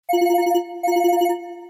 Kategorien: Sms Töne